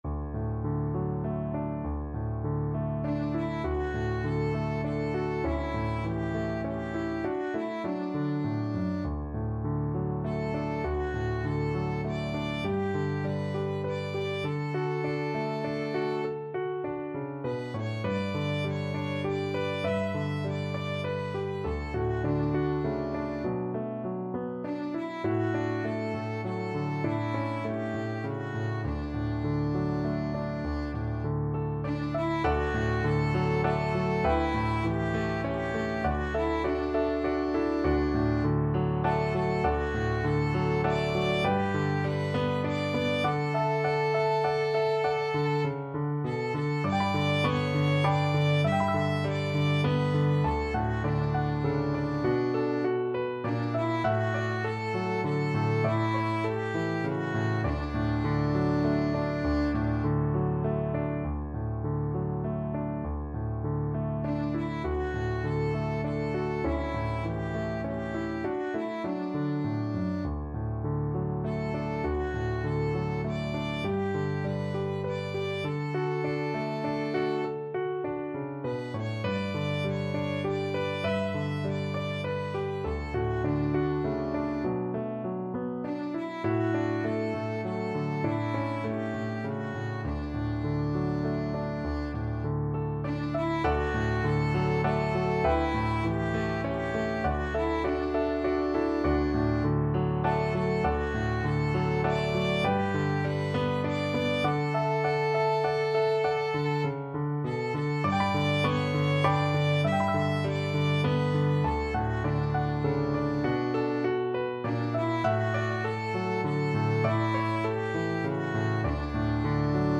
Violin
D major (Sounding Pitch) (View more D major Music for Violin )
Longingly, nostalgically =c.100
3/4 (View more 3/4 Music)
D5-E6
Traditional (View more Traditional Violin Music)
Australian
botany_bay_VLN.mp3